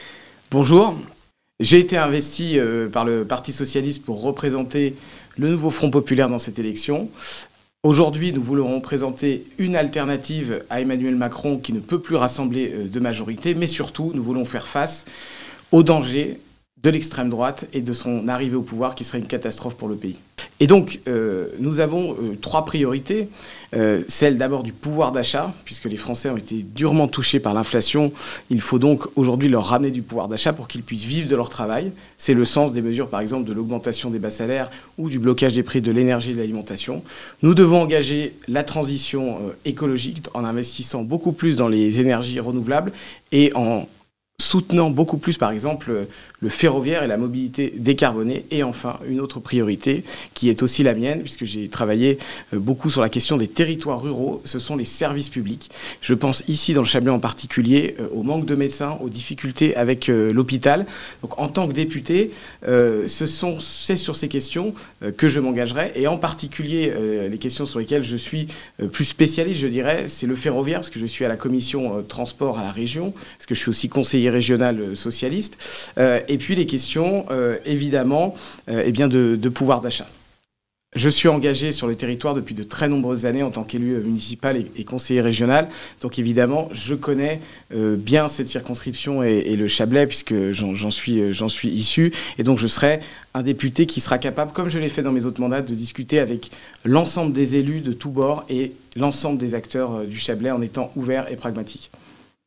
Gros plan sur les candidats du Chablais (interviews)
Voici les interviews des 8 candidats de cette 5ème circonscription de Haute-Savoie (par ordre du tirage officiel de la Préfecture)